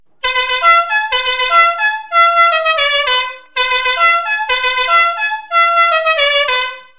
Fisa luchthoorn Metal 24V - La Cucaracha | 146124C-24V